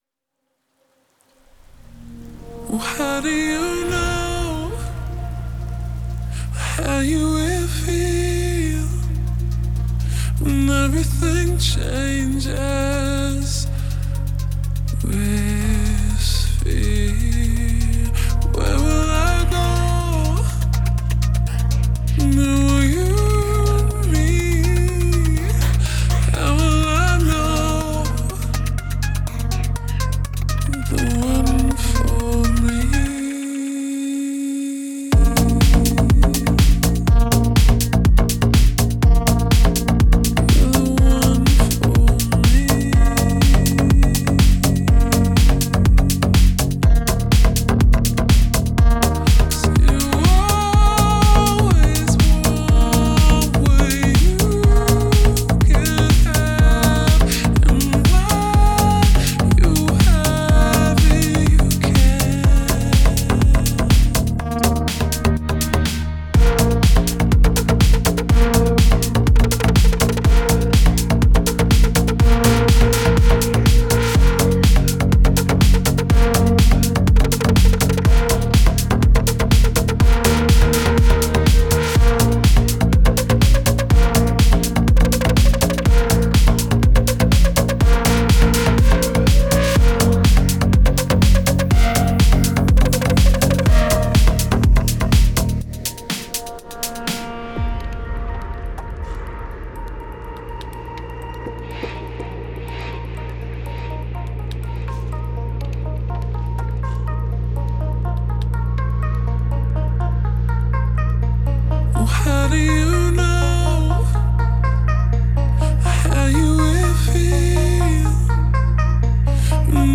энергичная поп-танцевальная композиция